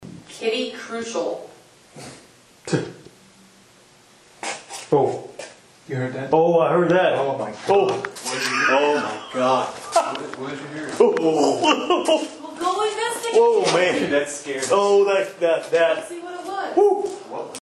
EVPs
Woman humming
While in the basement, some of the team heard an audible manifestation of a woman humming and the recorder picked it up
Audible-manifestation-Woman-hum-basement.mp3